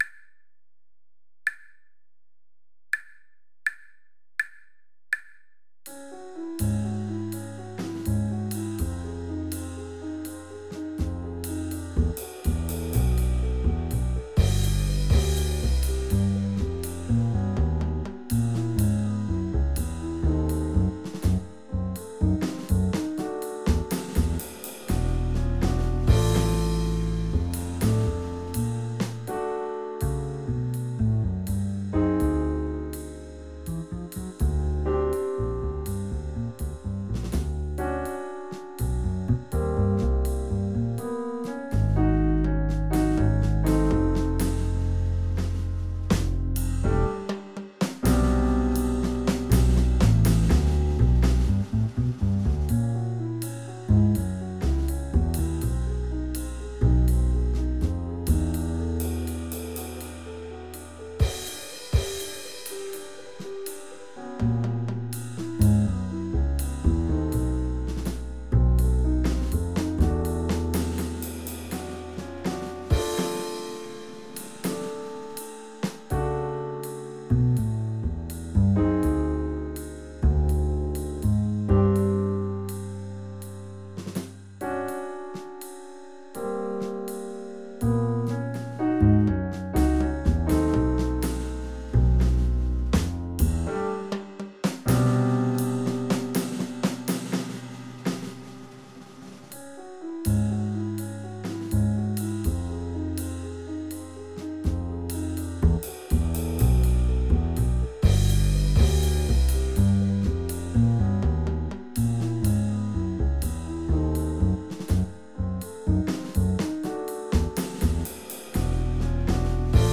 (Click on the above musical example to hear the related play-along: a six-chorus accompaniment audio track I’ve created.
a Carter-like bass-line alternates with a chorus of a more literal bass-line so as to provide you with two harmonic underpinnings to prompt your explorations.
You’ll likely find the recommended modes and scales to be a bit more dissonant with the freer bass-line and more consonant with the literal bass.)